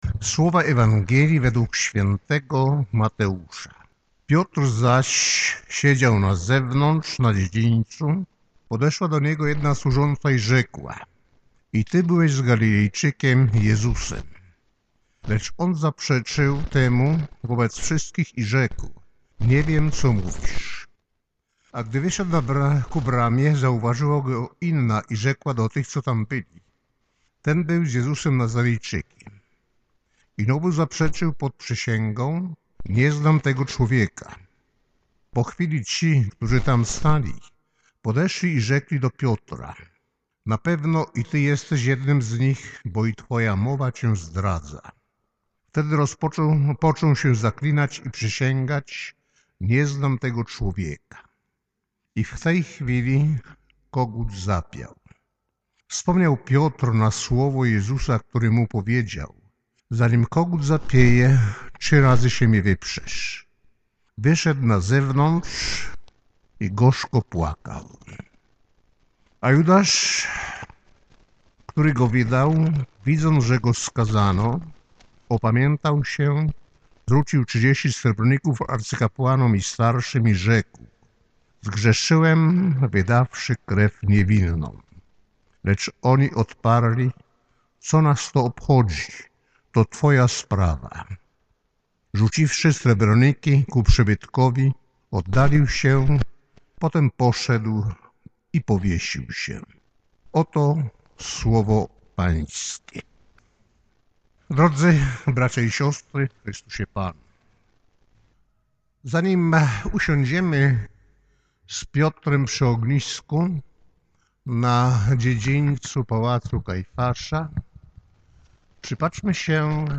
3__Kazanie_Pasyjne.mp3